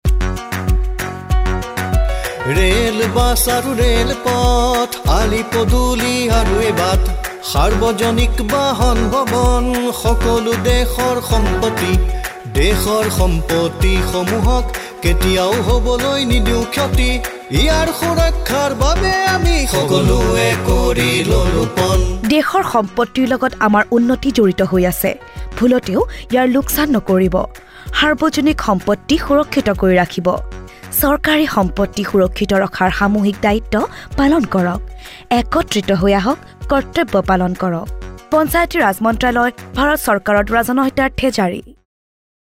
33 Fundamental Duty 9th Fundamental Duty Safeguard public property Radio Jingle Assamese